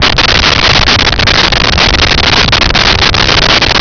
Sfx Amb Earthquake Loop
sfx_amb_earthquake_loop.wav